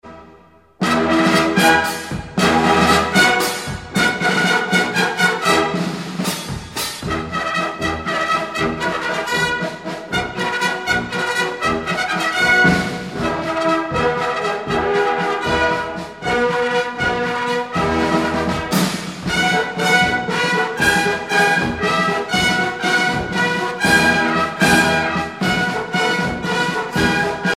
danse : valse
circonstance : militaire
Pièce musicale éditée